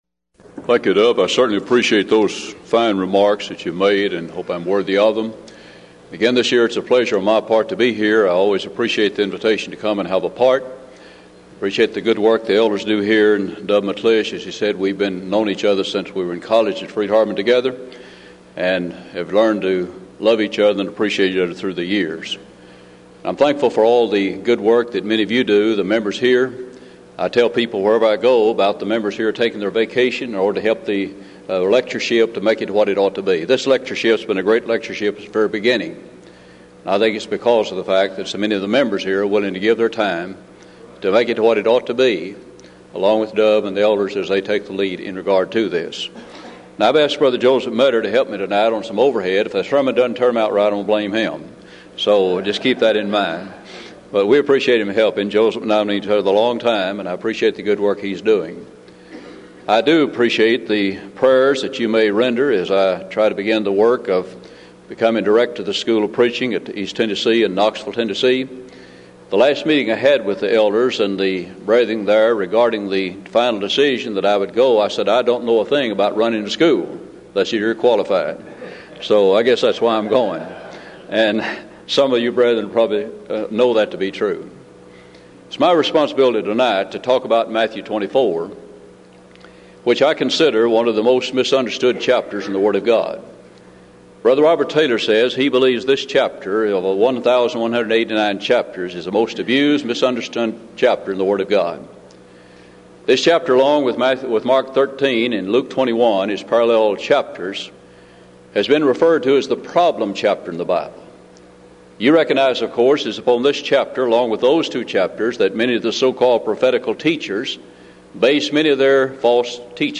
Event: 1995 Denton Lectures
lecture